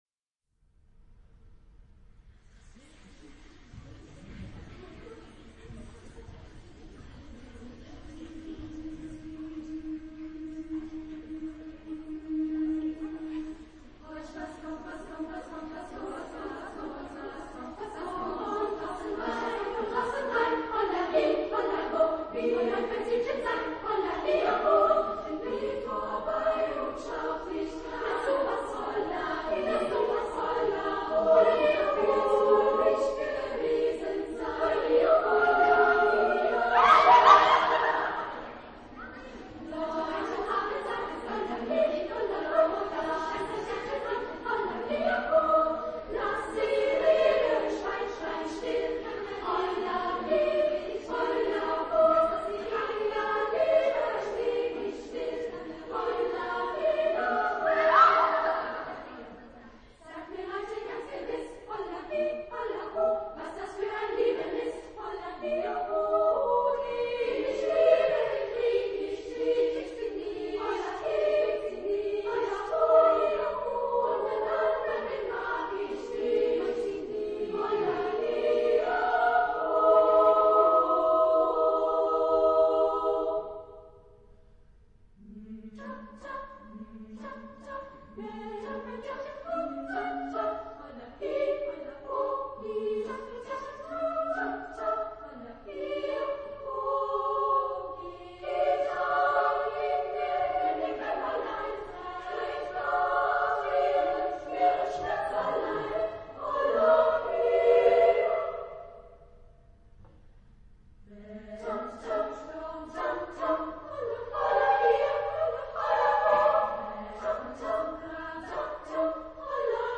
... Studentenlied aus der badischen Pfalz ...
Genre-Style-Form: Partsong ; Folk music ; Secular
Type of Choir: SSAA  (4 children OR women voices )
Tonality: D major
Discographic ref. : 7. Deutscher Chorwettbewerb 2006 Kiel